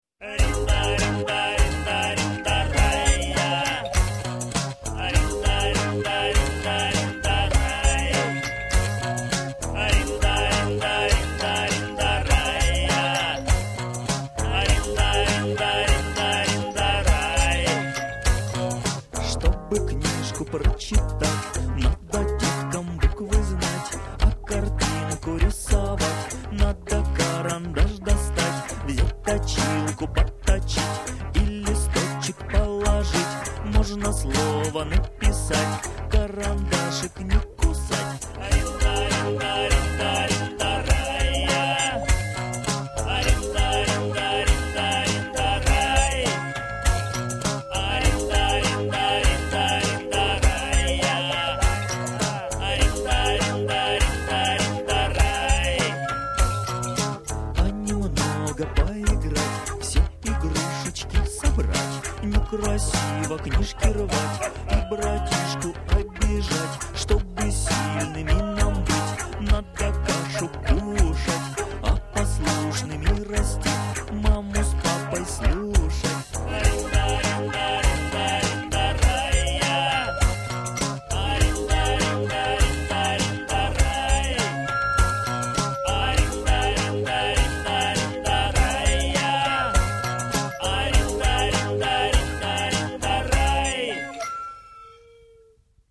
песни для детей